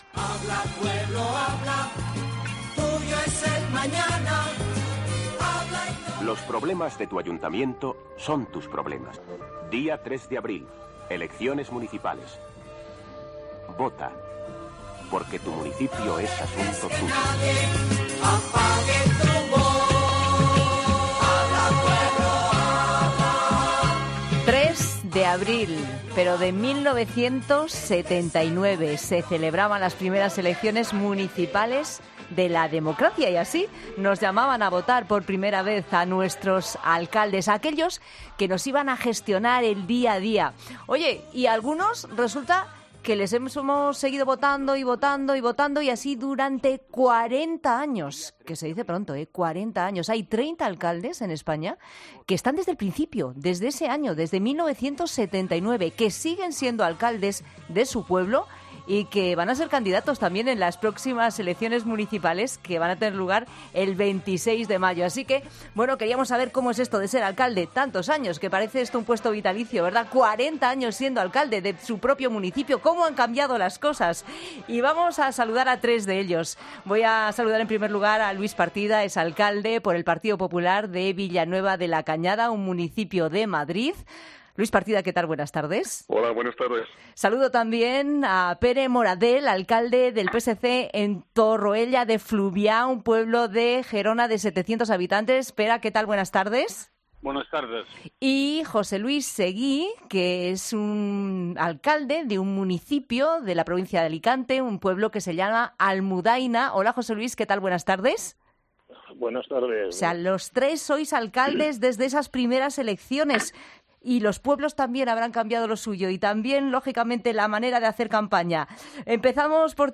Por ‘La Tarde’ han pasado tres ediles que desde hace cuatro décadas dirigen su pueblo.